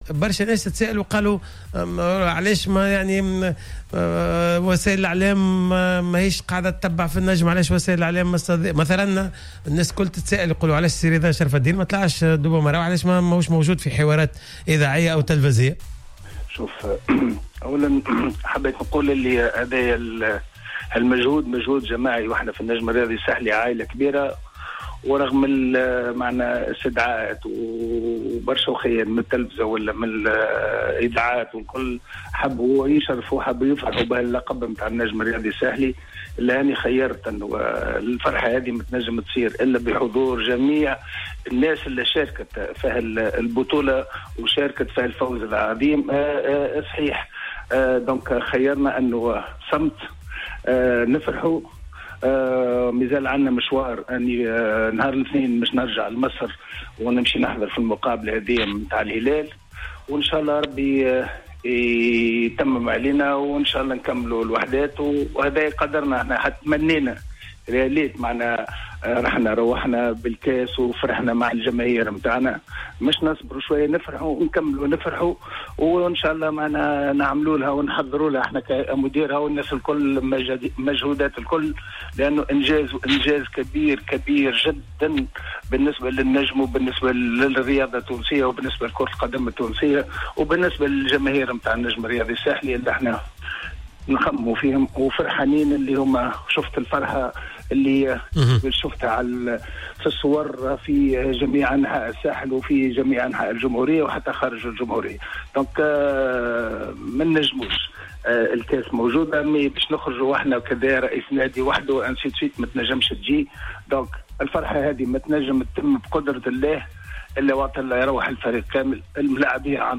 أكد رئيس النجم الساحلي الدكتور رضا شرف الدين في مداخلة في حصة "Planète Sport" اليوم السبت 20 أفريل 2019 أن الهيئة بصدد إعداد برنامج للإحتفال بإحراز النجم على اللقب العربي للمرة الأولى في تاريخ الفريق.